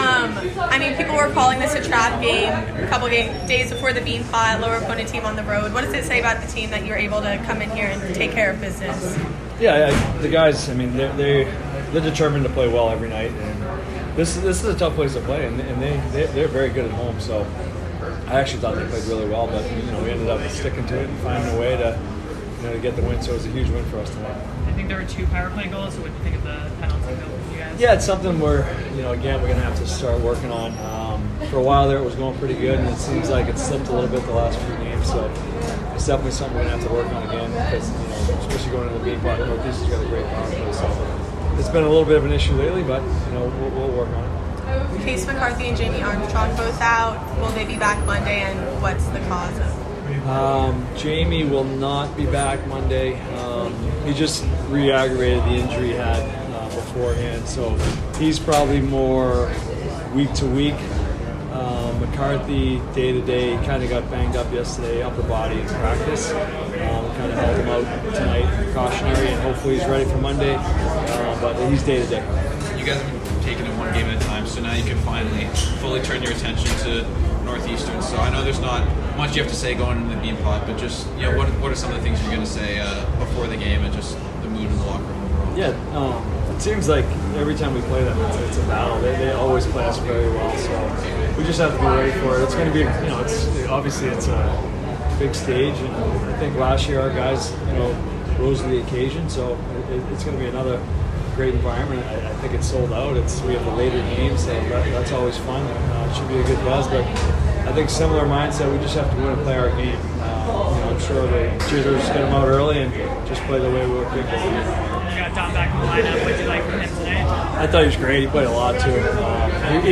Men's Ice Hockey / Maine Postgame Interview